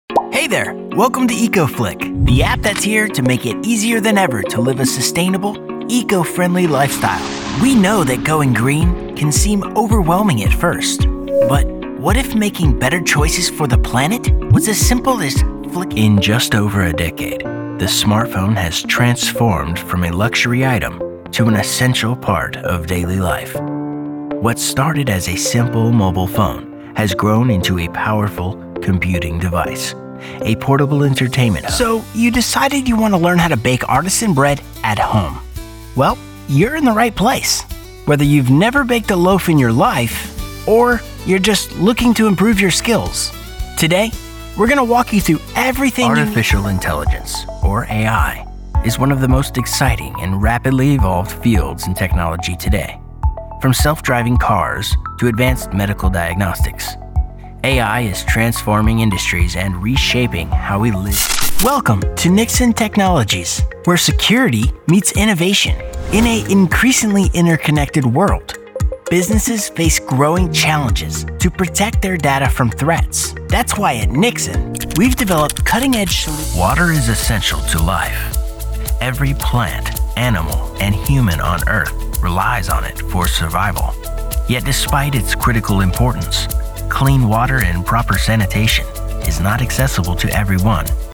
voz de barítono dinámica y áspera con un estilo auténtico y conversacional, ideal para anuncios, narraciones y personajes.
Vídeos explicativos
Tengo un estudio de grabación profesional en casa, así como sólidas habilidades de mezcla y masterización.